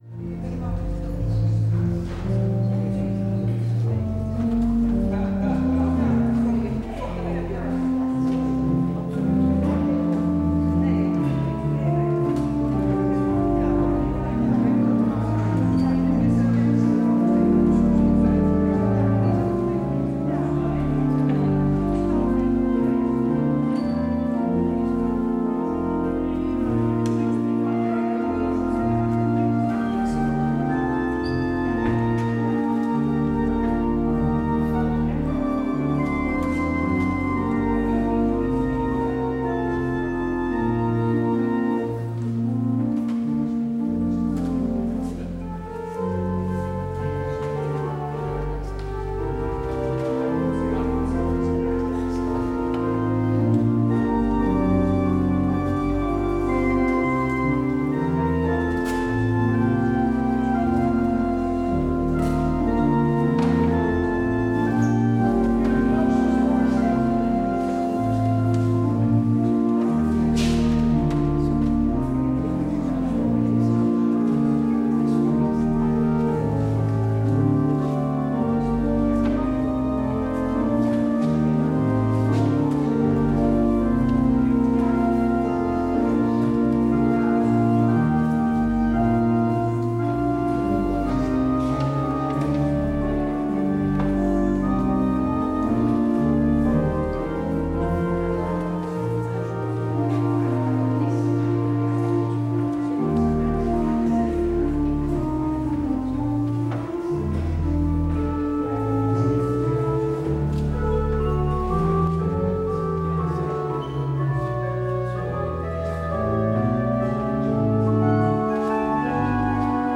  Beluister deze kerkdienst hier: Alle-Dag-Kerk 17 december 2025 Alle-Dag-Kerk https